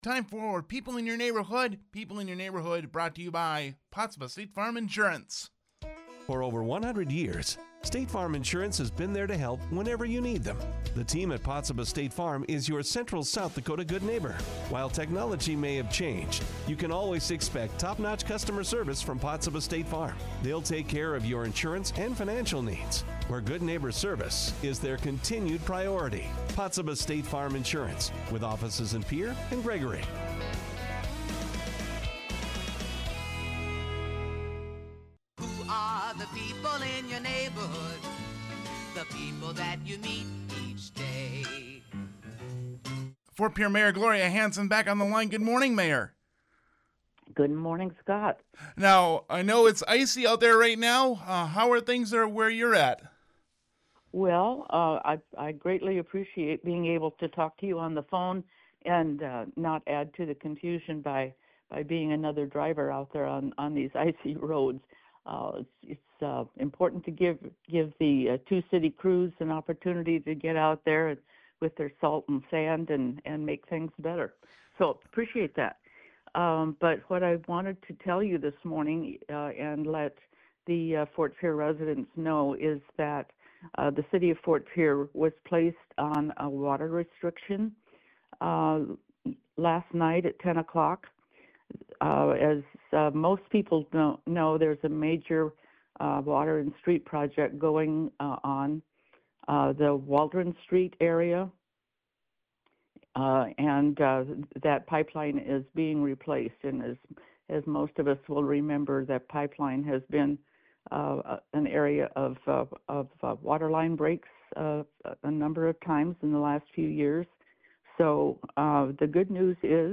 Ft. Pierre mayor Gloria Hanson joined People in Your Neighborhood to talk about the latest happenings in Ft. Pierre.